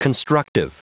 Prononciation du mot constructive en anglais (fichier audio)
Prononciation du mot : constructive